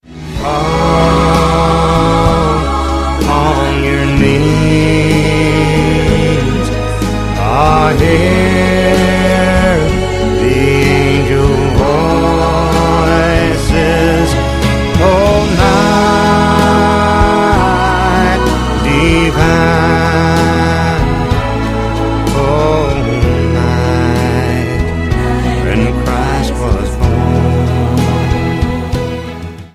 • Sachgebiet: Advent/Weihnachten Musik (Christmas)